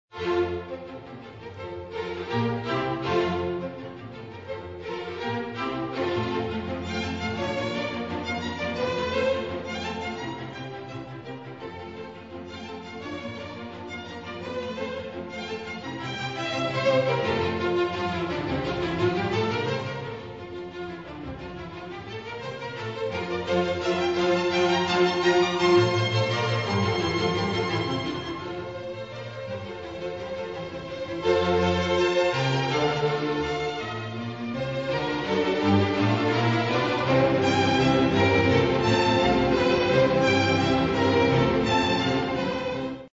Orchestraln quartet in F Major op. 4/4 (Independent Chamber Orchestra Boemia, cond.